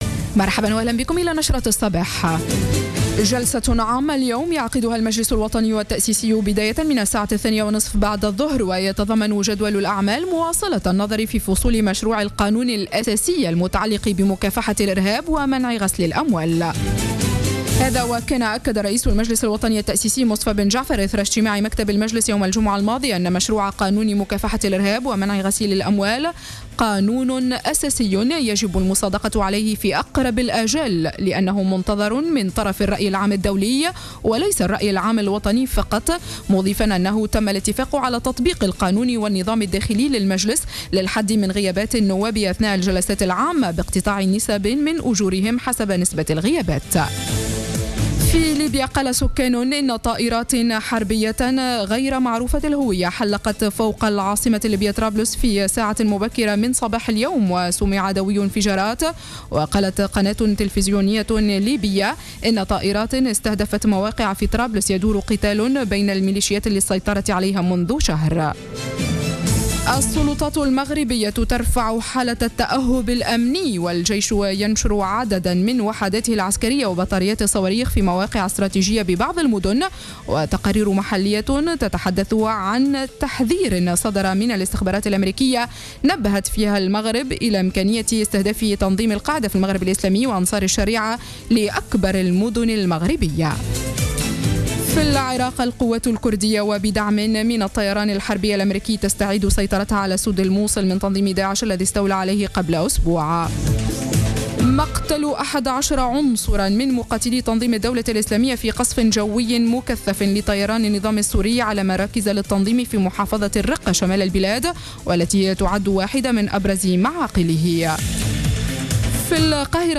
نشرة أخبار السابعة صباحا ليوم الإثنين 18-08-14